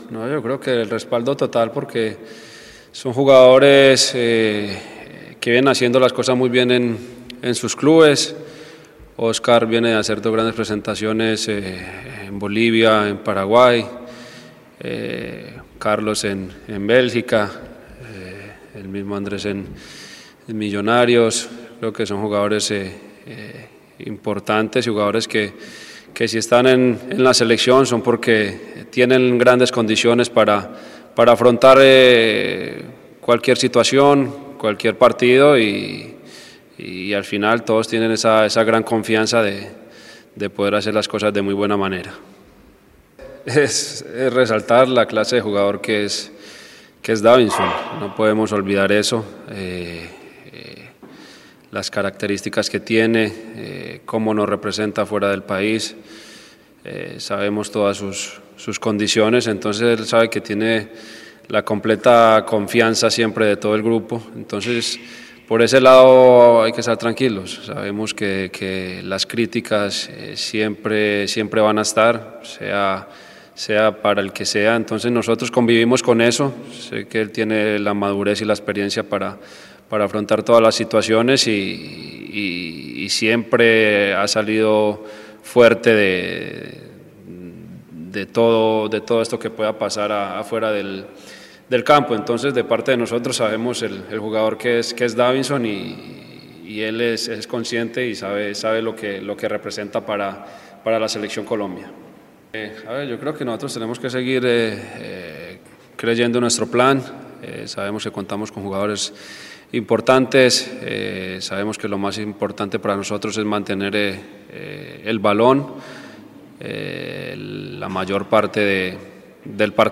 Falcao García y David Ospina, hablaron en rueda de prensa previo al juego que tendrá la selección Colombia este jueves ante Chile, por las Eliminatorias camino a Qatar 2022.